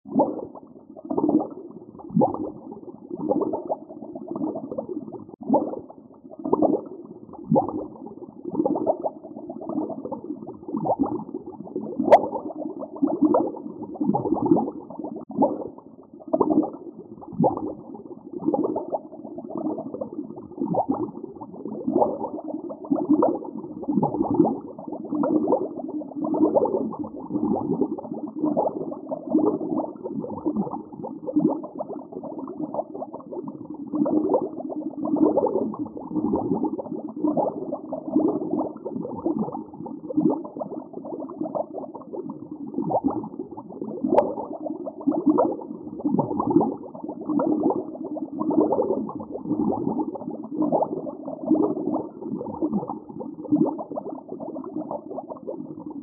連続した泡がブクブクと上へと上っていく時の音。夏にぴったりの涼しげな泡音。